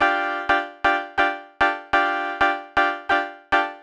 cch_synth_kiss_125_Dm.wav